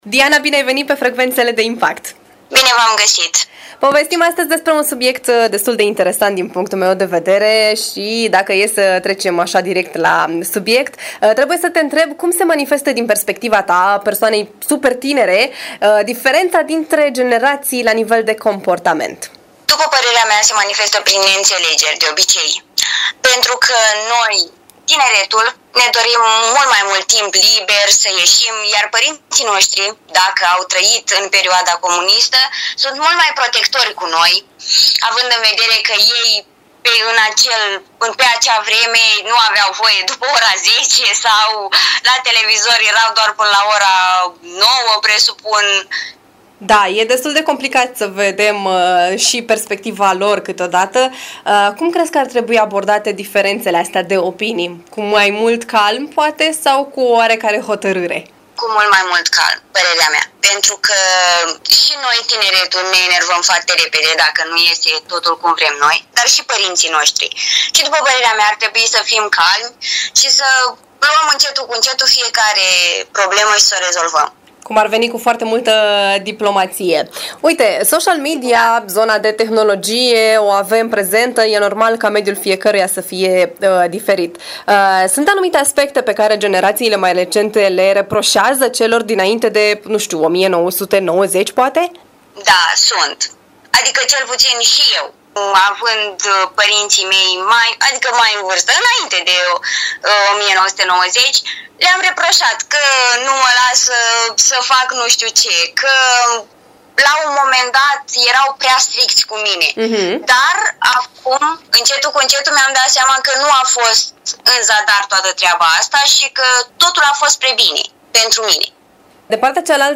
Tocmai acest subiect am ales să îl abordăm și în cel mai recent interviu de pe frecvențe.